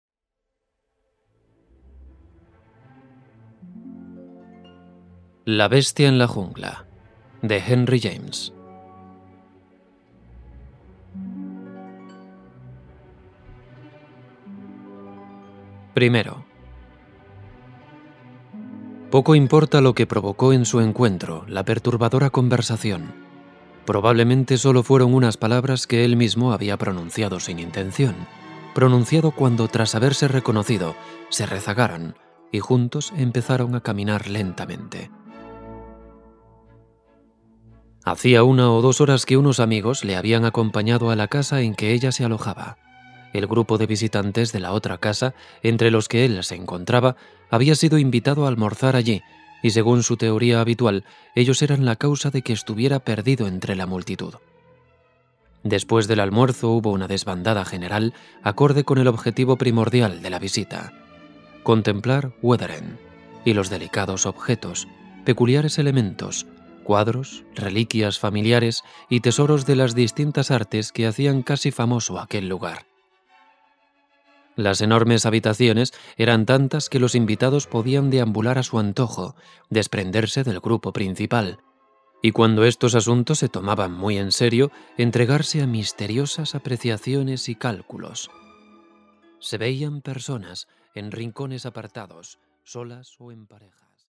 Música: Classiccat (cc:by-sa)
Esperamos que ahora ustedes puedan disfrutar con esta cuidada adaptación en formato audiolibro.